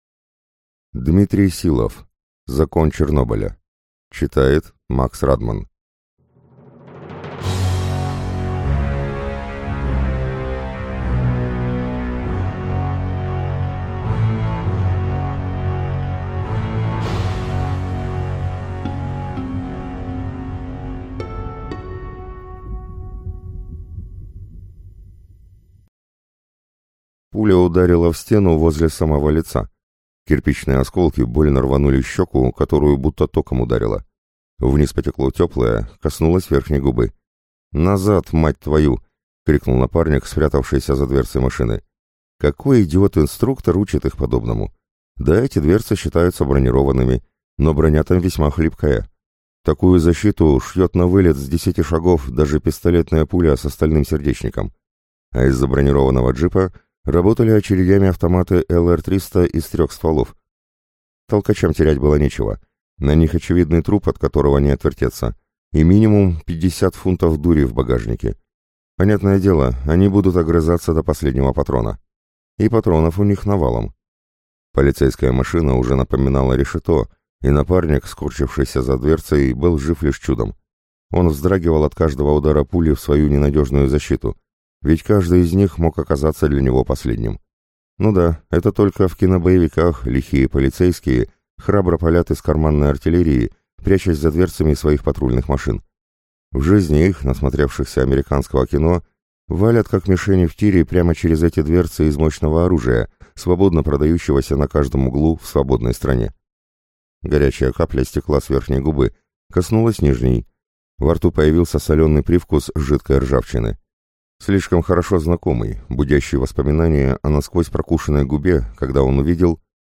Аудиокнига Закон Чернобыля | Библиотека аудиокниг